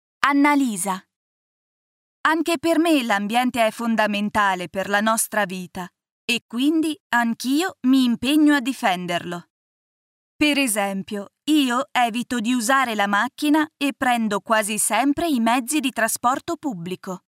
Speaker italiana, voce giovane e versatile, voci bambini e caratterizzazioni.
Sprechprobe: eLearning (Muttersprache):